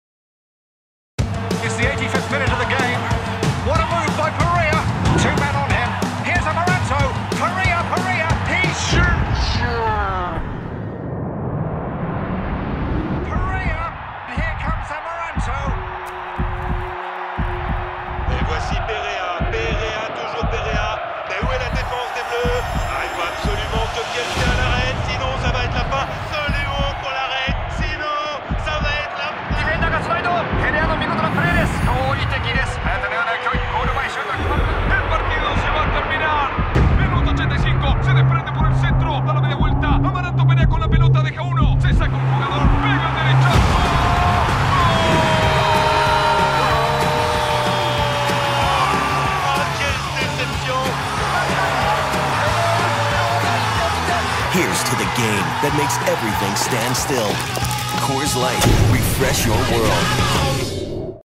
Male
French (European), French (Parisienne)
Adult (30-50), Older Sound (50+)
Bass, deep and warm, I can sound either calm and reassuring or upbeat and energetic.
Words that describe my voice are French, Bass, Deep.